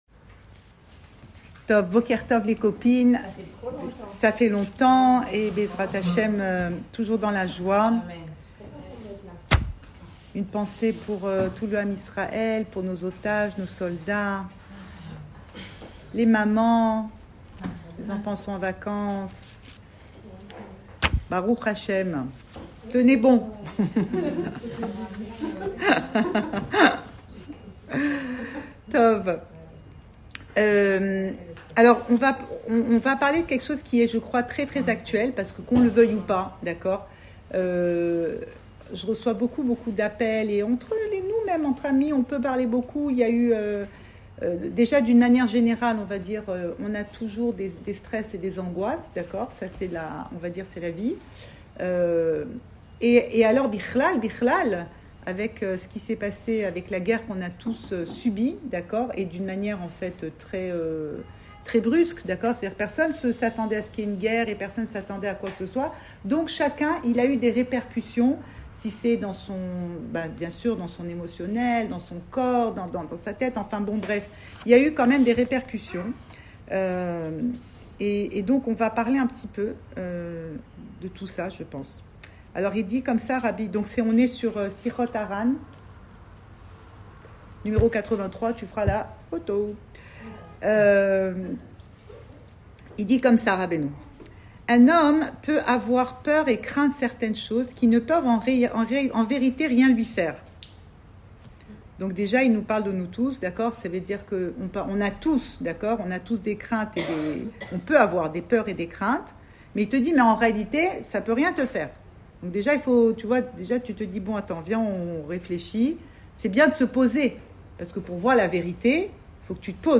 Cours audio Emouna Le coin des femmes Le fil de l'info Pensée Breslev - 2 juillet 2025 2 juillet 2025 Fugacité. Enregistré à Tel Aviv